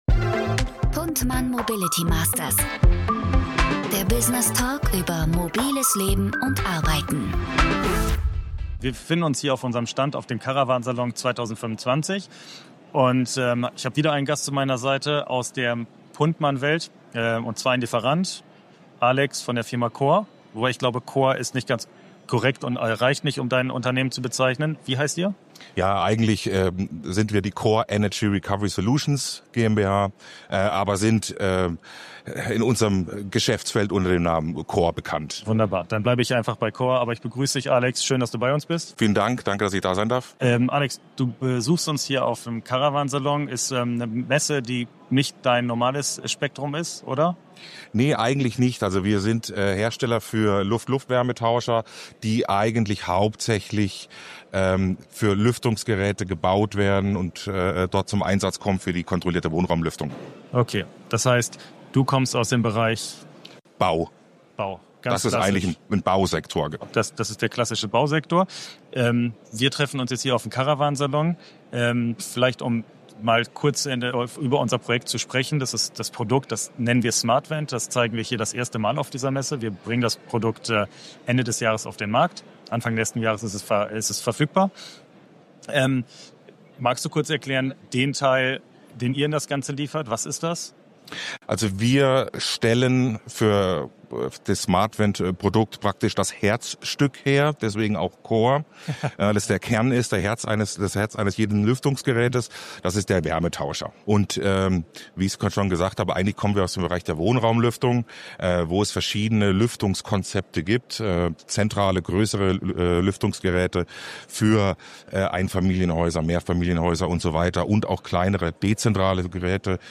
In dieser Folge von Mobility Masters melden wir uns vom Caravan Salon 2025 in Düsseldorf!